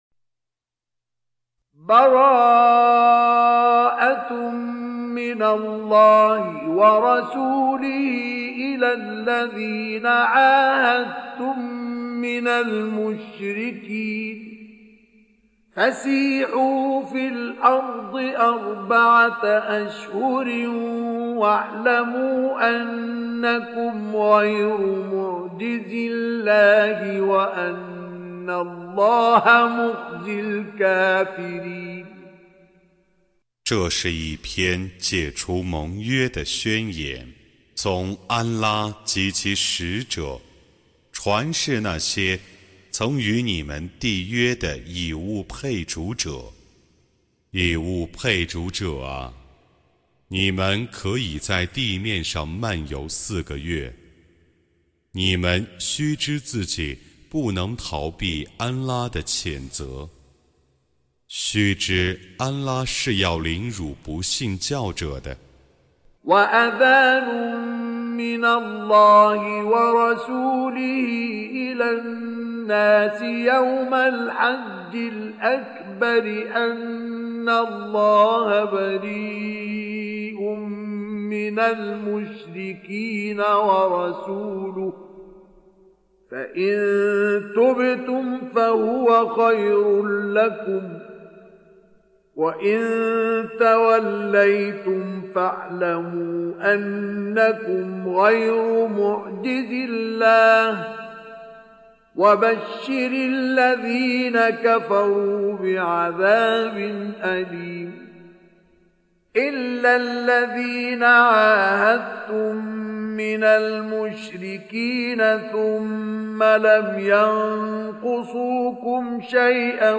Surah Repeating تكرار السورة Download Surah حمّل السورة Reciting Mutarjamah Translation Audio for 9. Surah At-Taubah سورة التوبة N.B *Surah Excludes Al-Basmalah Reciters Sequents تتابع التلاوات Reciters Repeats تكرار التلاوات